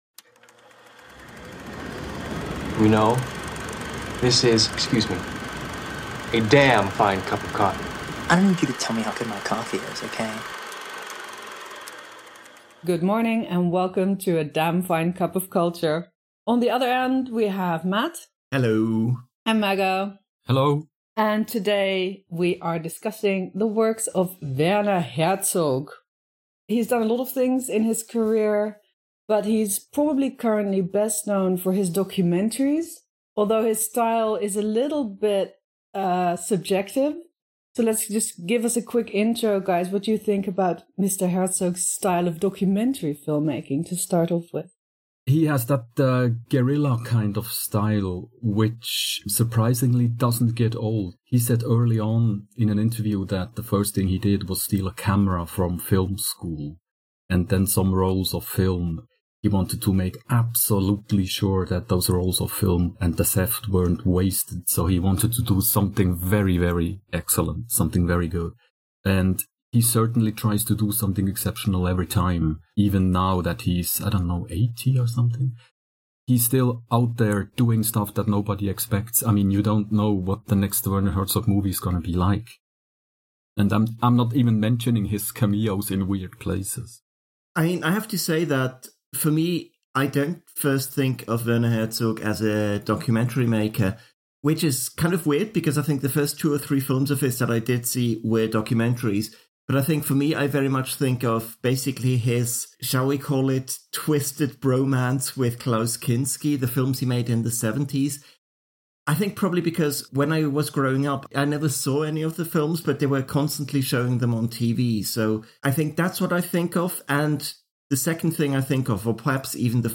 Join your cultural baristas for a conversation about Werner Herzog and his films, ranging from Nosferatu the Vampyre (1978) via Grizzly Man (2005) to Encounters at the End of the World (2007).